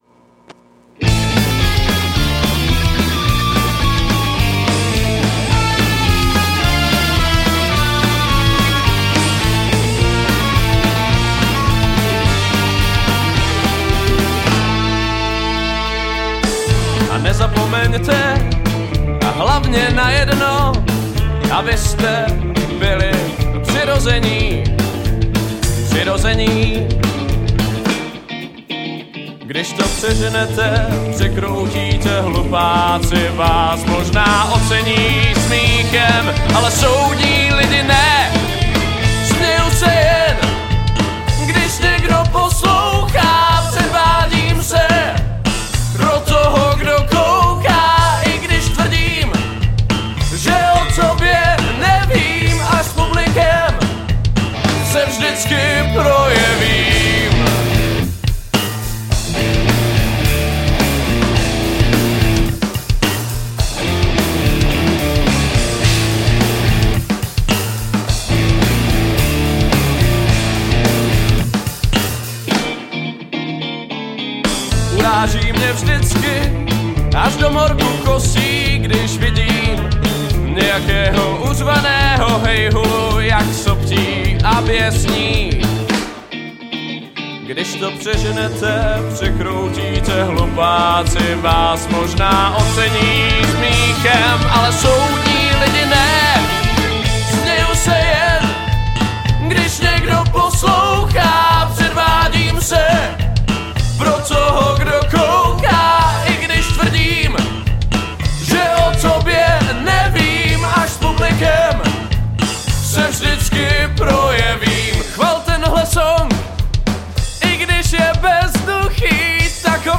Žánr: Rock
Heavy rockabilly.